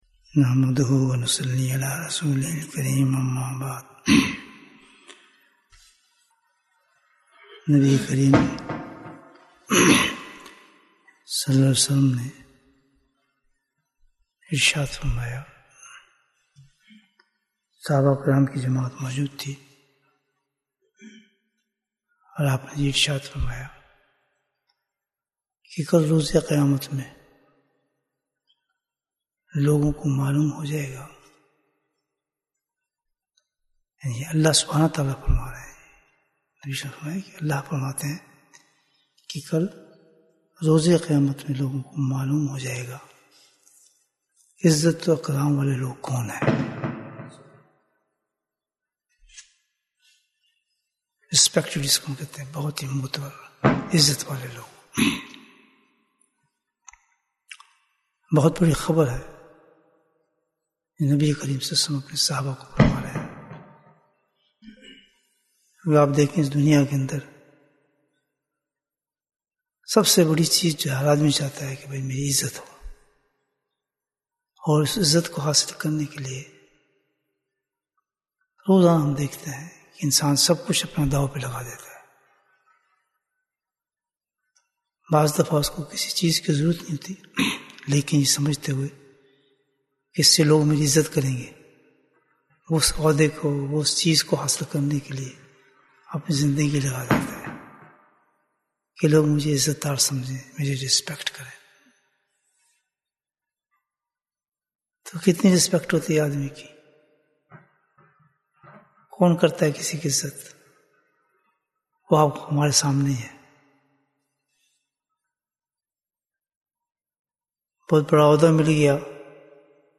عزت دار لوگ کون ہیں؟ Bayan, 25 minutes18th October, 2022